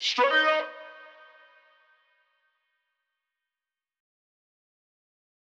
Metro Vox 3.wav